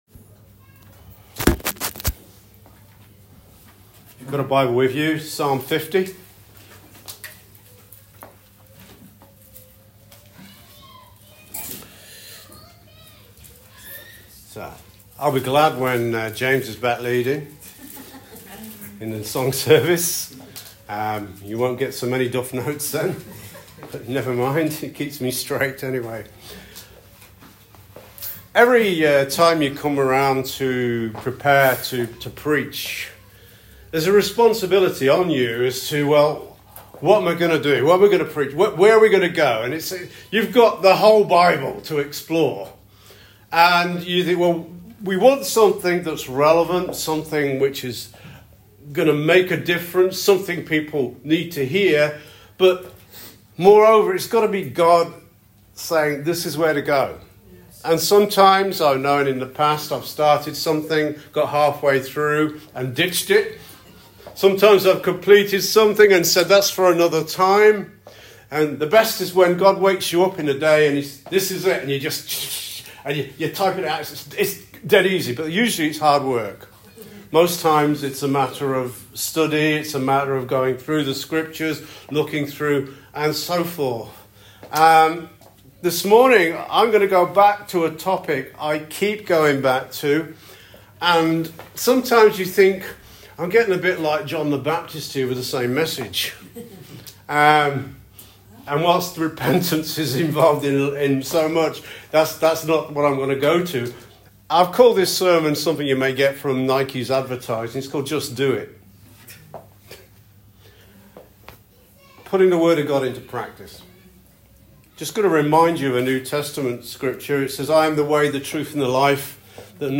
SERMON "JUST DO IT”